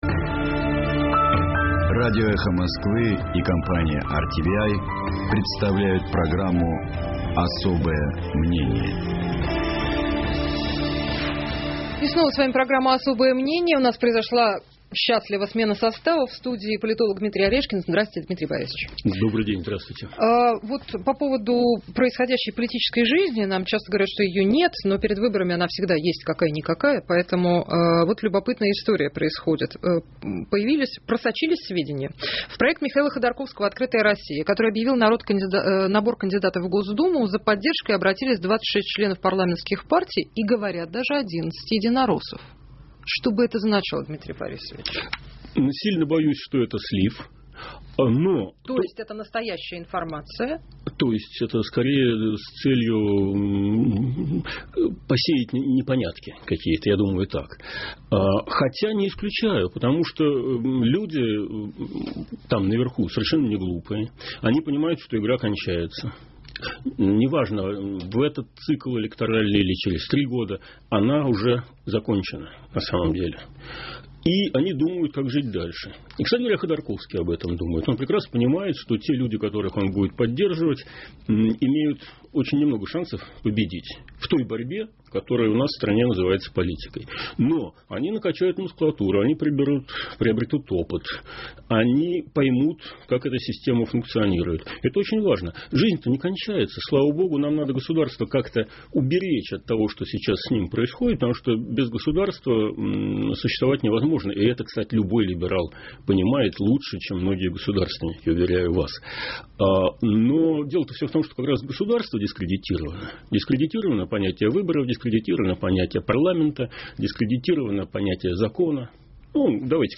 В студии - политолог Дмитрий Орешкин.